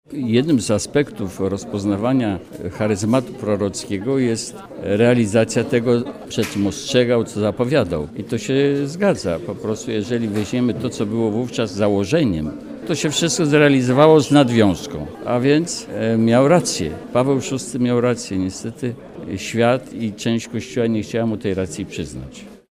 W Sekretariacie Episkopatu Polski w Warszawie odbyła się dziś sesja dla dziennikarzy poświęcona 50-tej rocznicy ogłoszenia encykliki papieża Pawła VI „Humanae vitae” .
Wśród uczestników sesji był arcybiskup Henryk Hoser, który encyklikę papieża Pawła VI nazwał dokumentem prorockim.